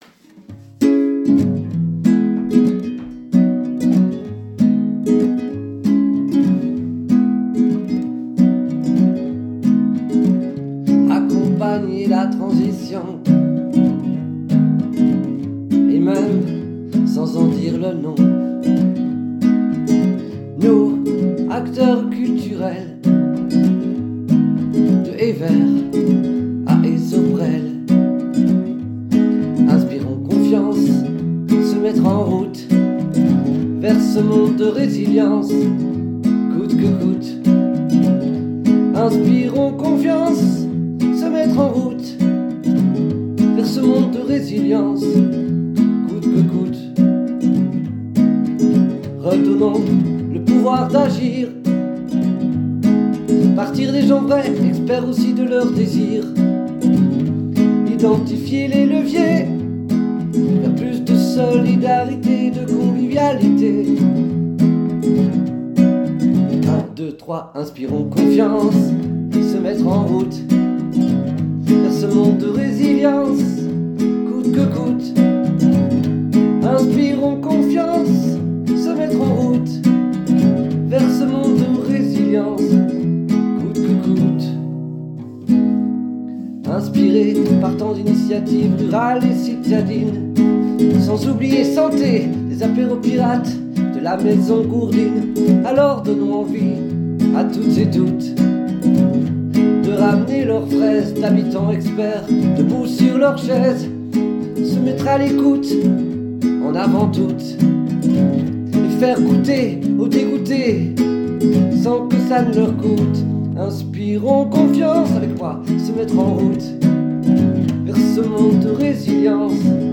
Le 21 novembre 2024, les Centres culturels de Walcourt, Florennes et Gerpinnes ont organisé en collaboration avec l’ASTRAC une journée pour les professionnel·le·s du secteur autour de la nécessaire transition écologique.
Au chant et à la guitare